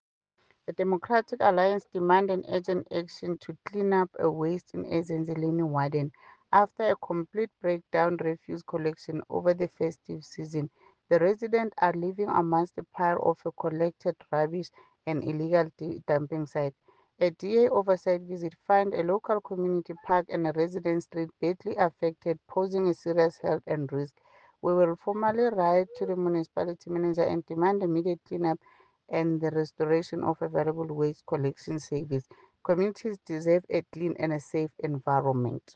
English soundbite by Cllr Ntombi Mokoena, Afrikaans soundbite by Cllr Anelia Smit and